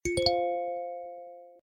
Twitter_Sound_Effect.ogg